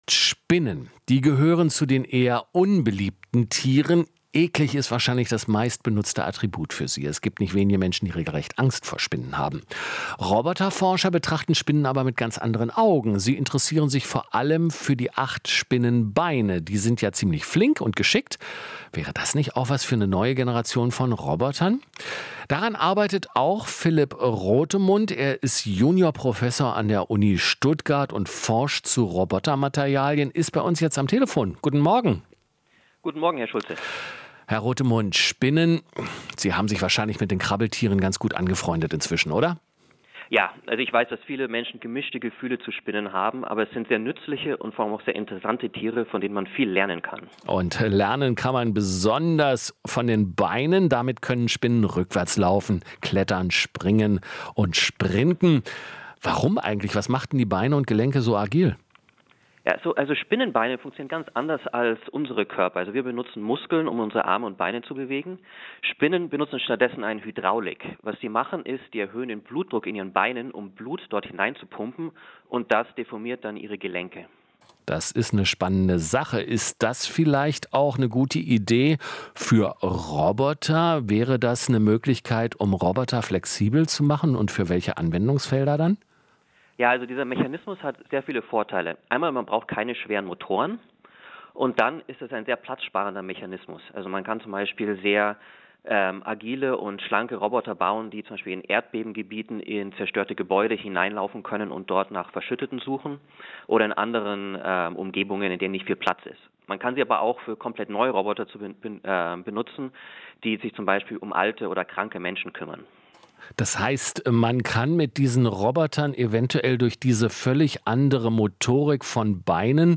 Interview - Was Roboterforscher von Spinnenbeinen lernen können